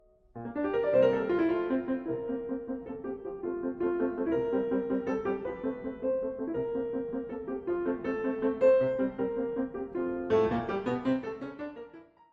Mozart, sonate in F-groot KV 332, derde deel, maat 15-22
Uitgevoerd door Maria Joćo Pires.